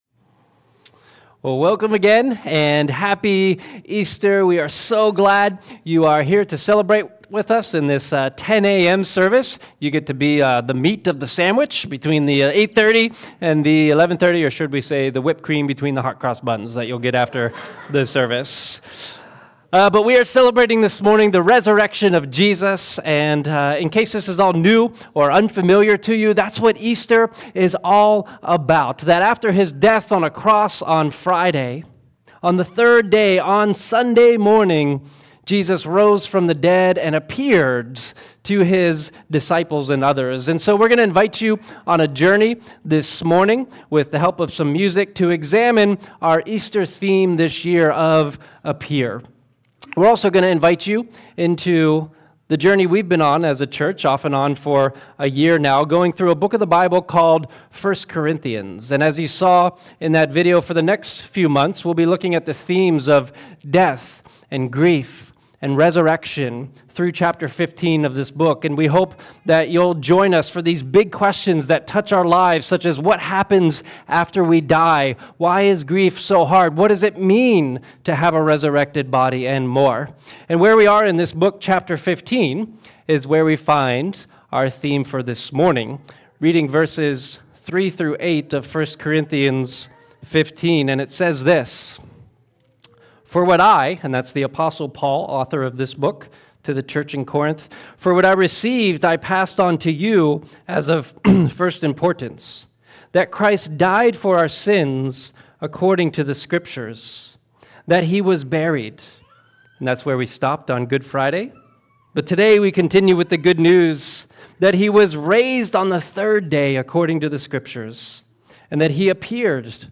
"Appear" Easter Sunday Message